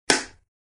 Звуки кинохлопушки
Кинохлопушка - Третий вариант с кинохлопушкой, но громче